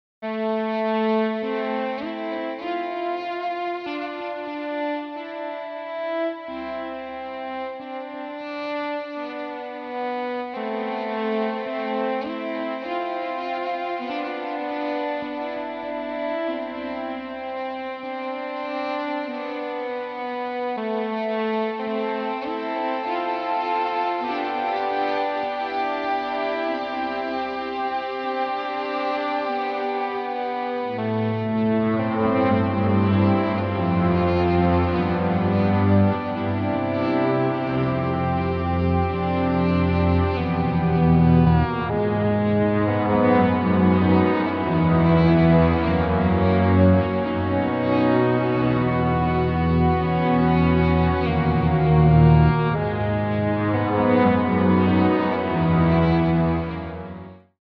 Ebow To Create String Quartet
Nano-Looper-360Looper-Ebow-To-Create-String-Quartet.mp3